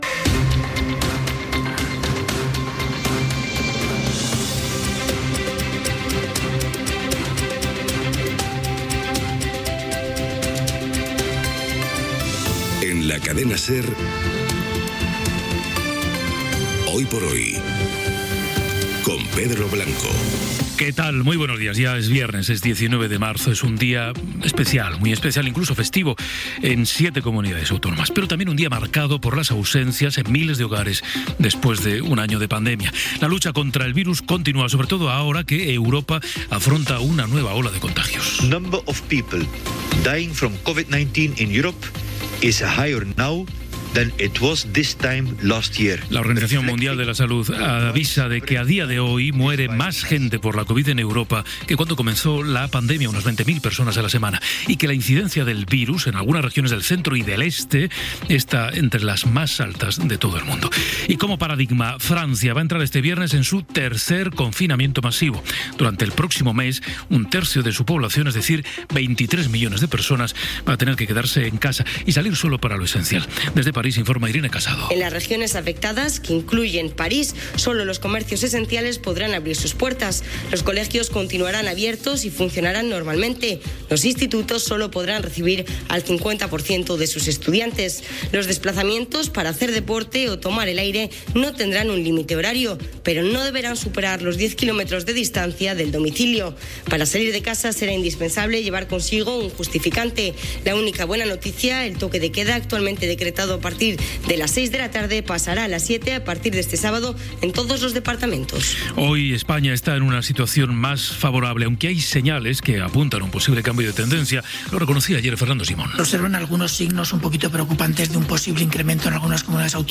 Indicatiu del programa, data, un any després de l'inici de la pandèmia de la Covid-19. Crònica des de París. El pont turístic i l'efecte de la pandèmia. Recomanacions del Banc d'Espanya. La vacuna europea, indicatiu del programa
Info-entreteniment
FM